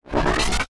railgun-turret-activate-1.ogg